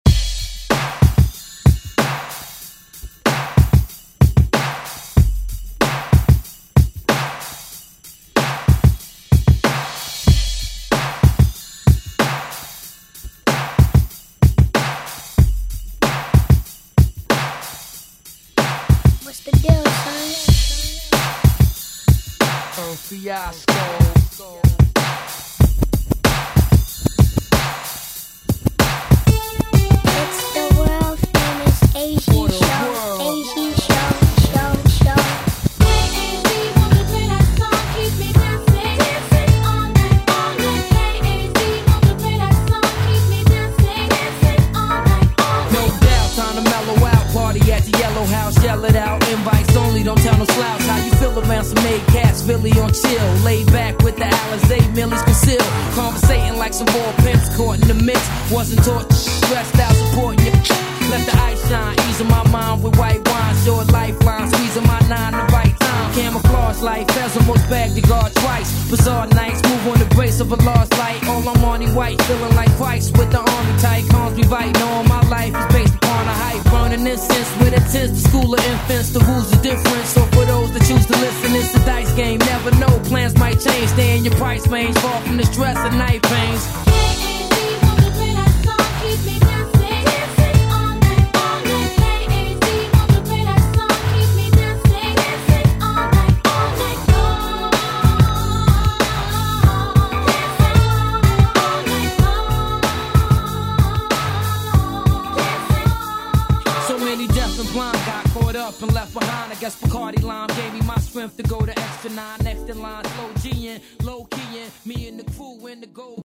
Electronic Pop Latin Salsa Music
Genre: 80's
Clean BPM: 110 Time